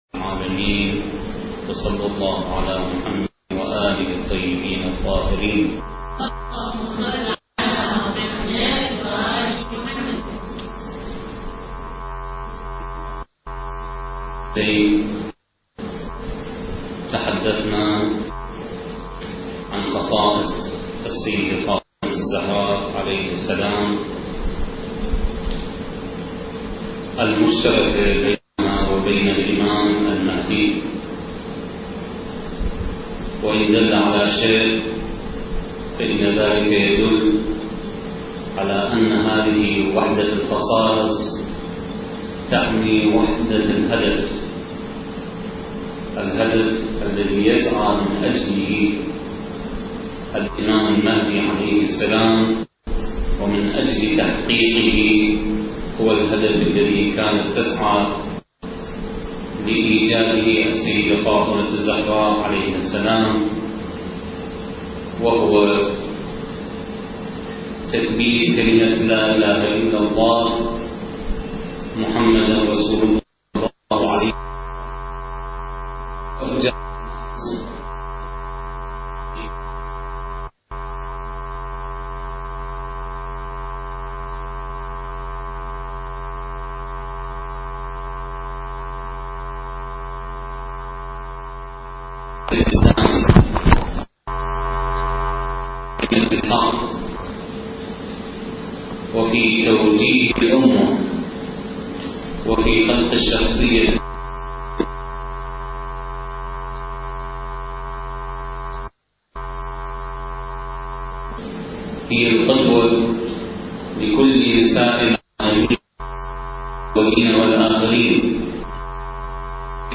سلسلة: المرأة والانتظار (1) الدورة الثقافية المهدوية للأخوات المؤمنات التي أقامها مركز الدراسات التخصصية في الإمام المهدي (عجّل الله فرجه) تحت شعار (بين صمود الانتظار وبشائر الظهور) التاريخ: 2006